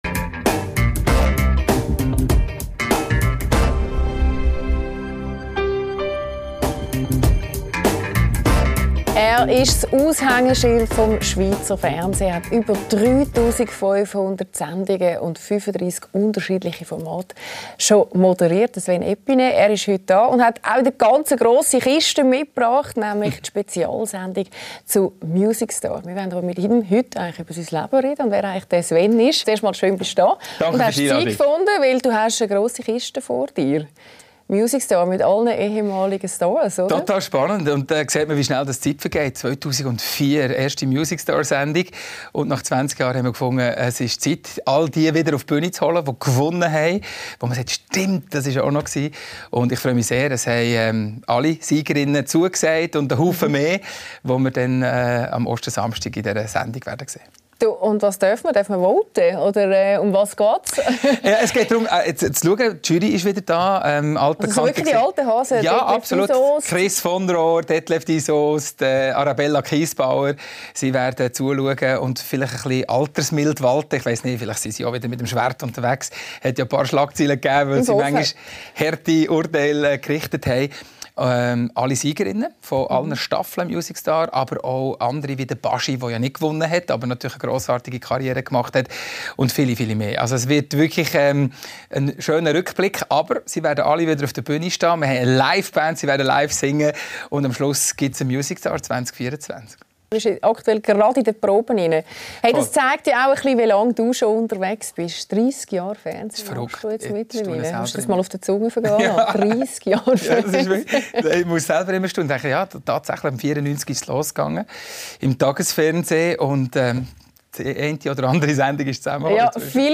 Mit Sven Epiney ~ LÄSSER ⎥ Die Talkshow Podcast